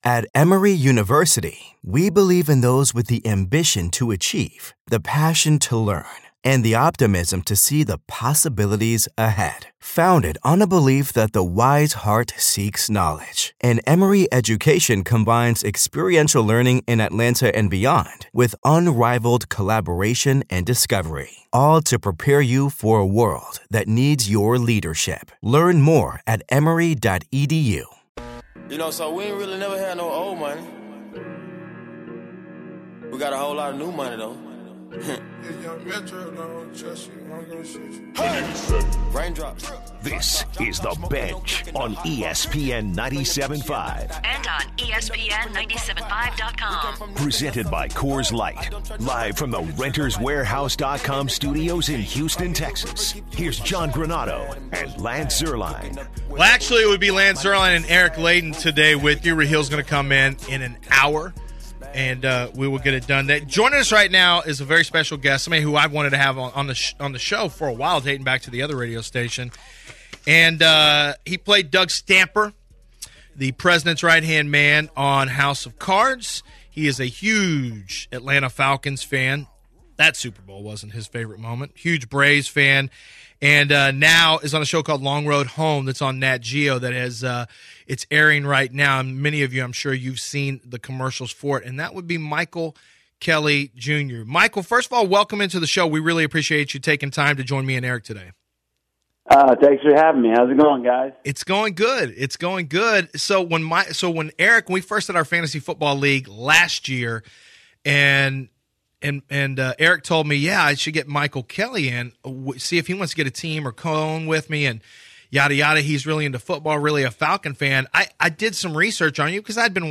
Michael Kelly Jr interview